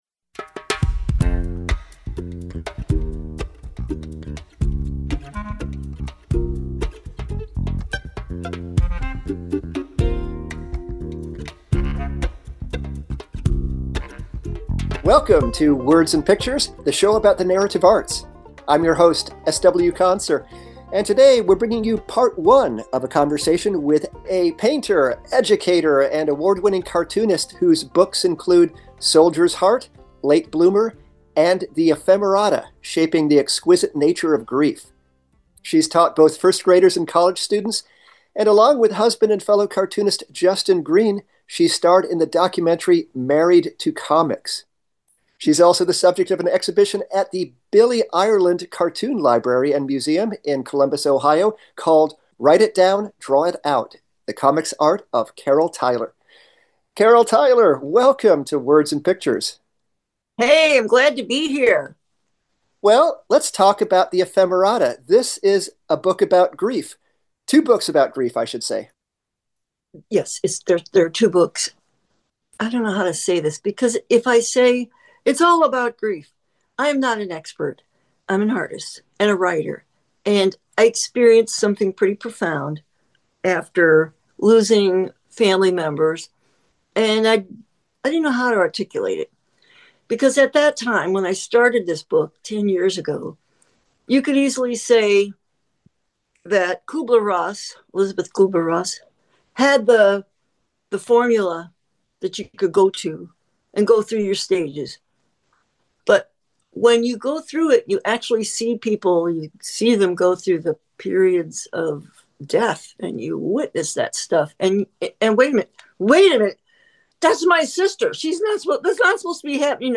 In part one of this two-part conversation